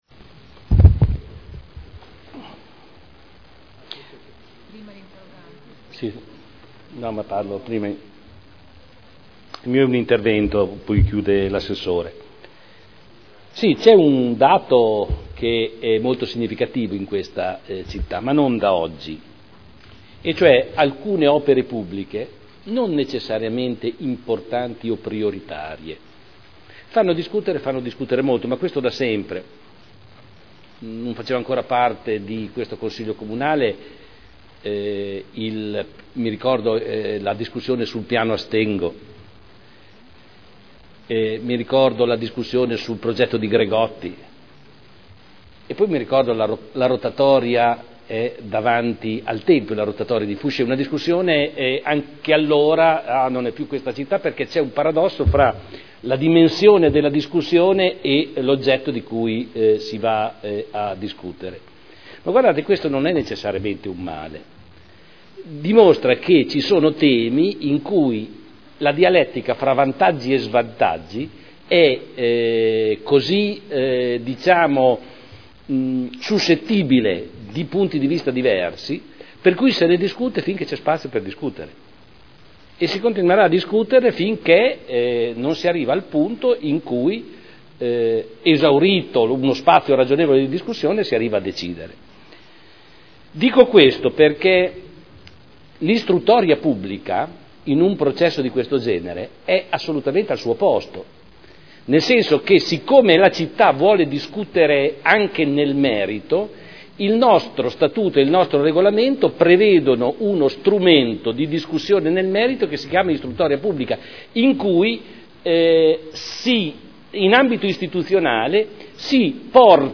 Seduta del 02/05/2011.